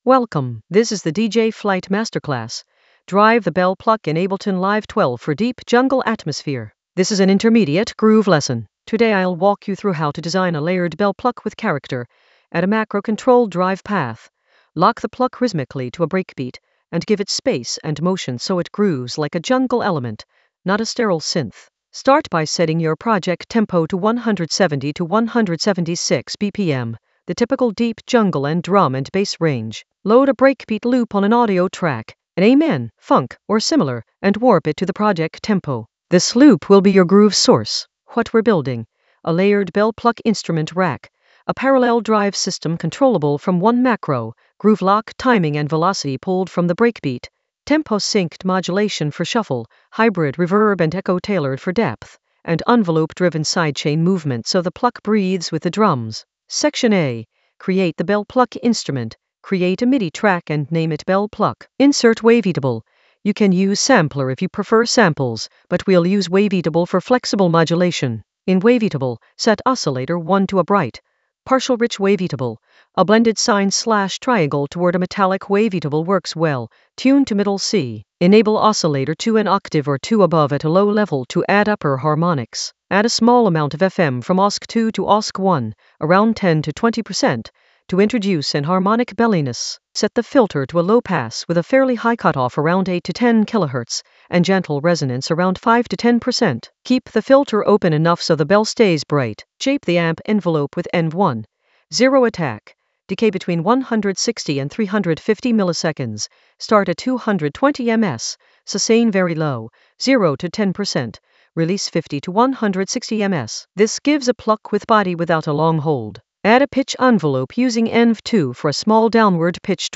Narrated lesson audio
The voice track includes the tutorial plus extra teacher commentary.
An AI-generated intermediate Ableton lesson focused on DJ Flight masterclass: drive the bell pluck in Ableton Live 12 for deep jungle atmosphere in the Groove area of drum and bass production.